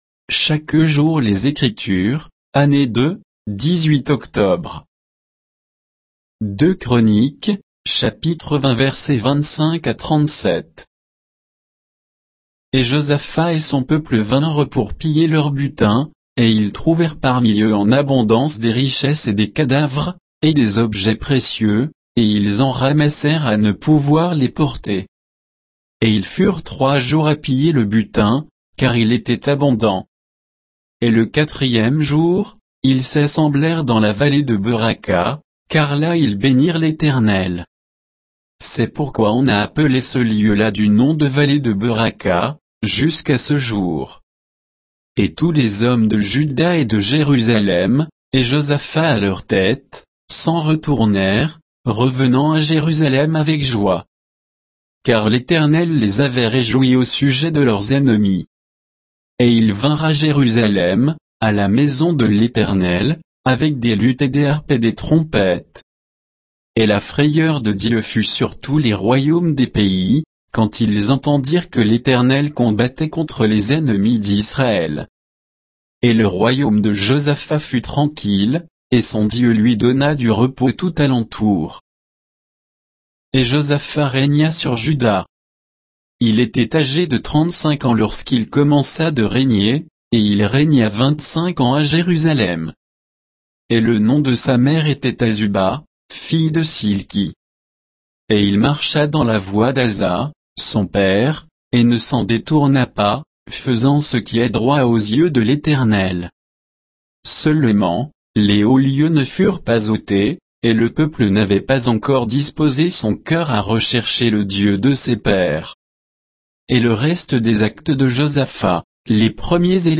Méditation quoditienne de Chaque jour les Écritures sur 2 Chroniques 20